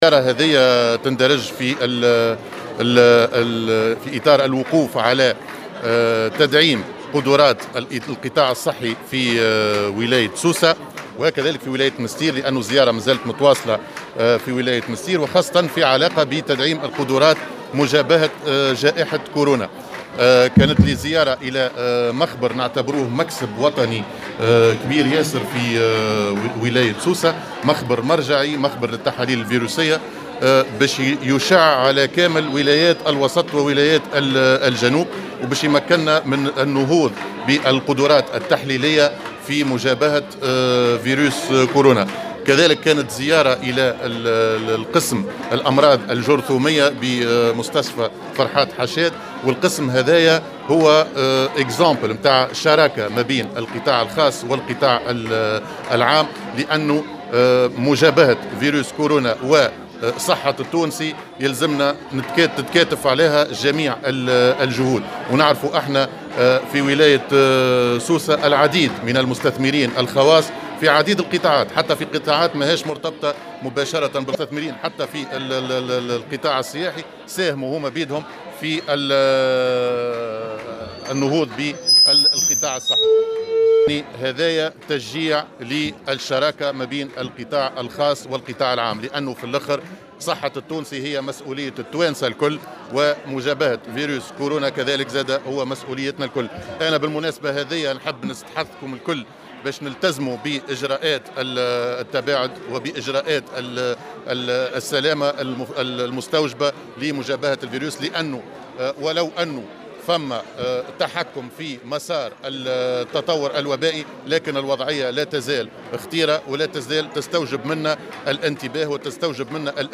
أكد رئيس الحكومة هشام المشيشي في تصريح للجوهرة "اف ام" على هامش زيارة أداها إلى سوسة اليوم الثلاثاء أن هذه الزيارة تندرج في إطار الوقوف على تدعيم القطاع الصحي بولايتي سوسة و المنستير في ظل مجابهة وباء كورونا.